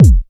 • 00's Electronic Kickdrum F Key 127.wav
Royality free kickdrum sound tuned to the F note. Loudest frequency: 254Hz